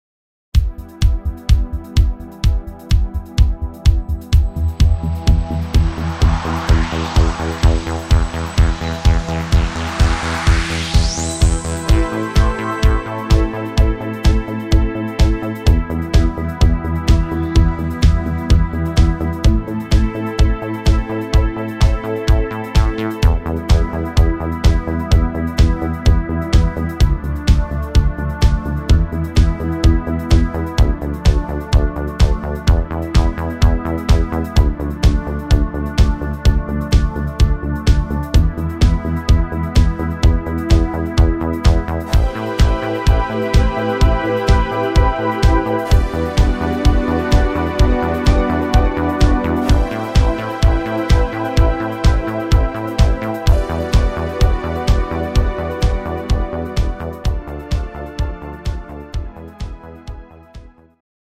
instr. Synthesizer